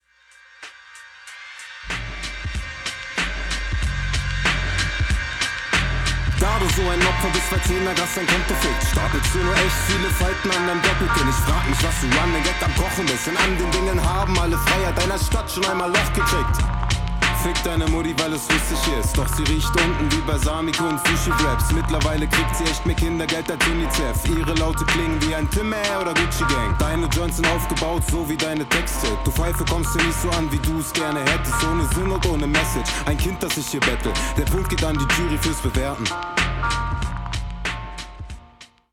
Die Runde ist sehr chillig, nett mit den …